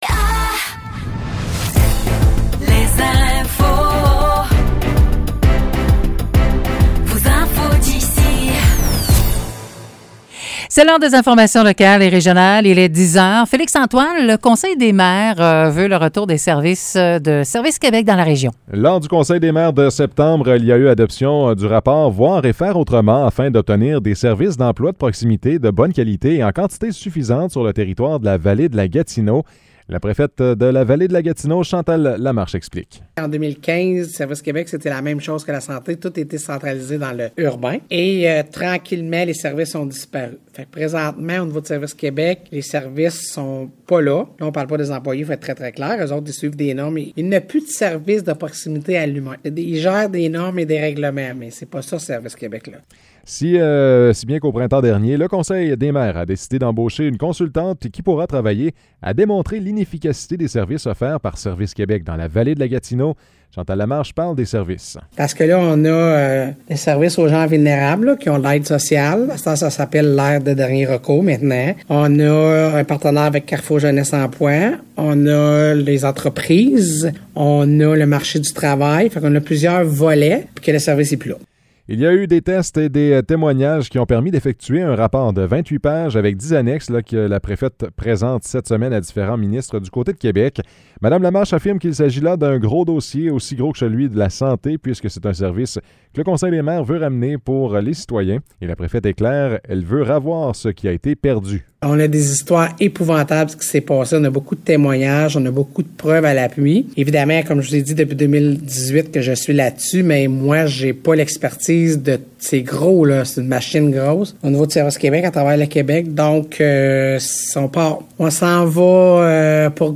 Nouvelles locales - 27 septembre 2023 - 10 h